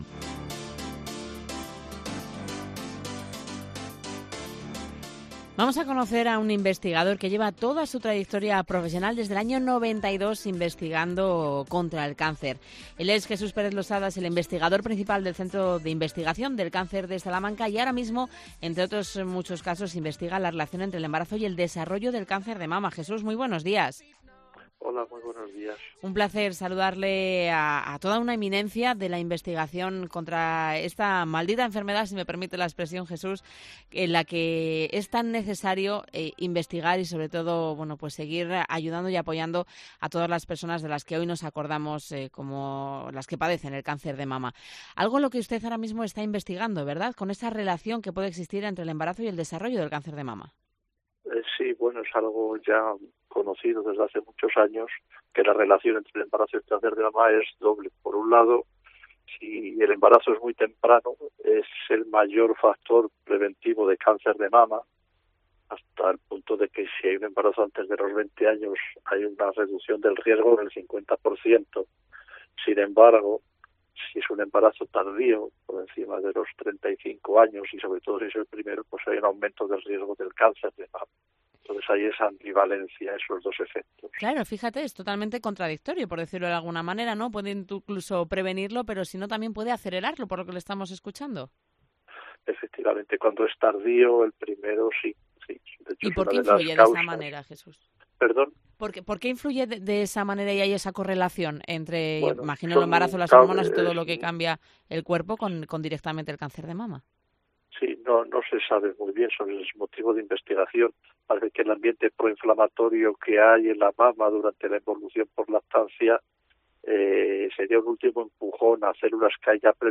Investigación